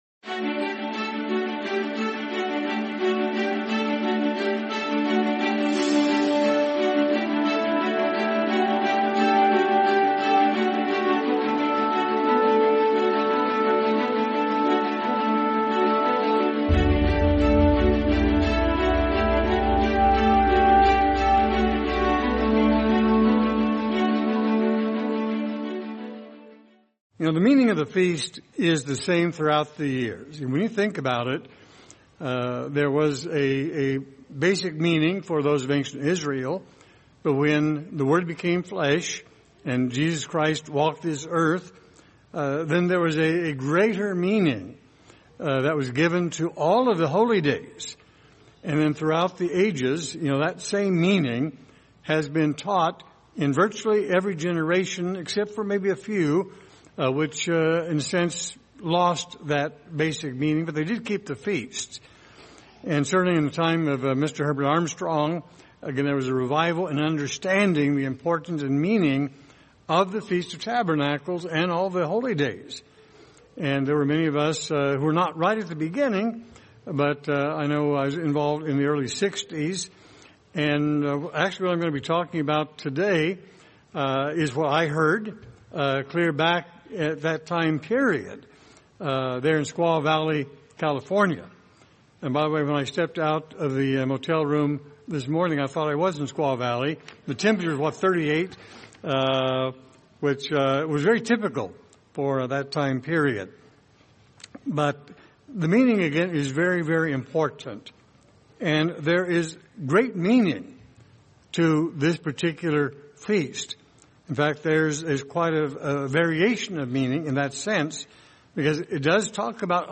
Sermon Feast of Tabernacles 2025: A Time of Rejoicing